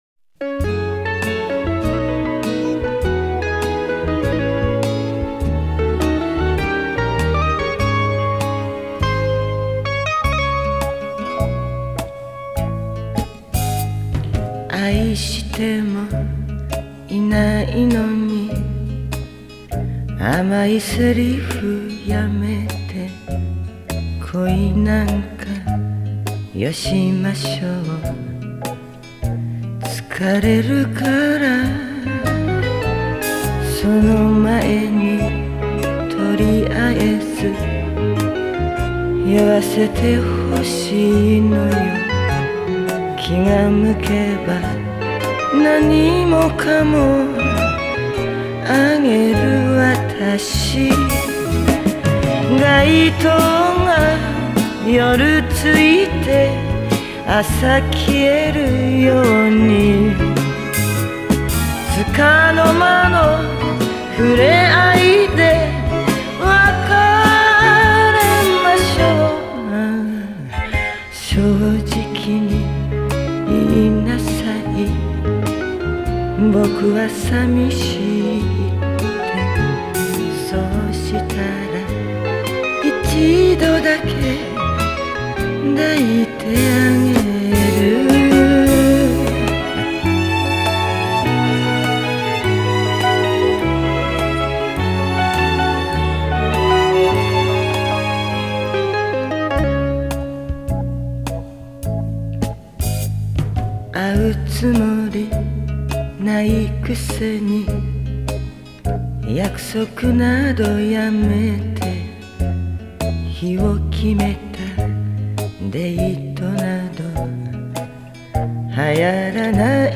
Genre:    Pop
Style:    Kayōkyoku